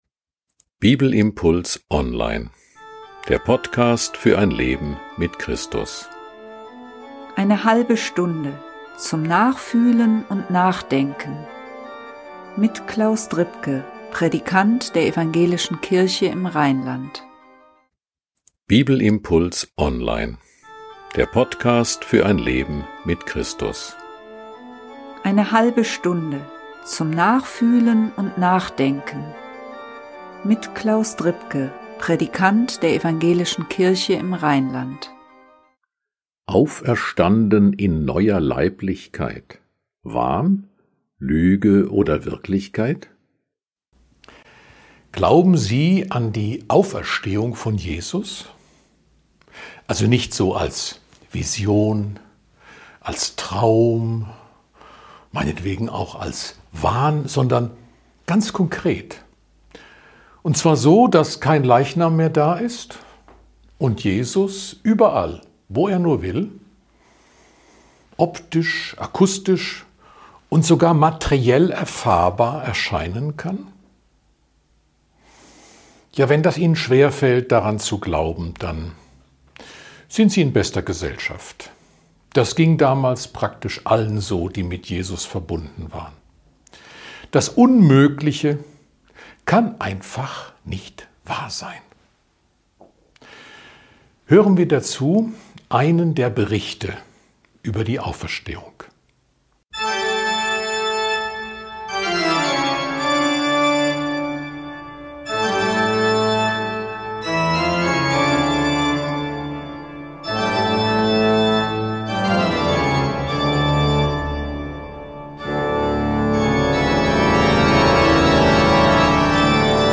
Ein Bibelimpuls zu Markus 16, 9-20.